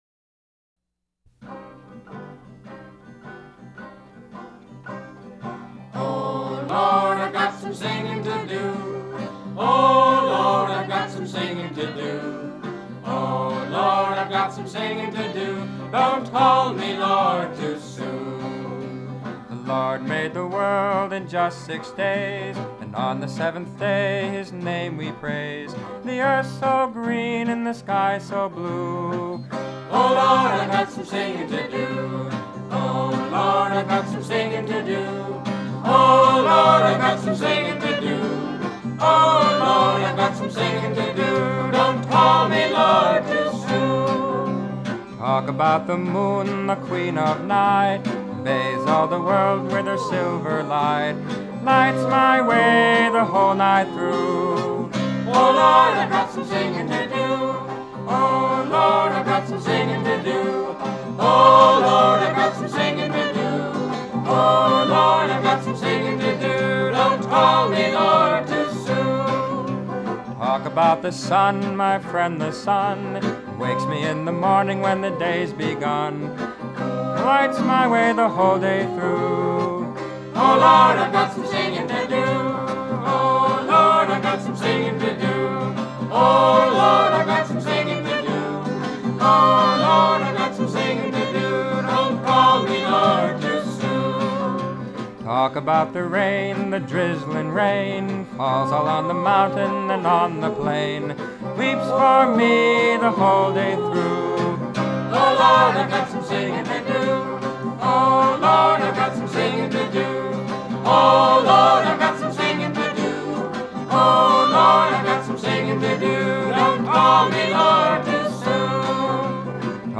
an MP3 file of a folk performance of the original song on which this new arrangement is based.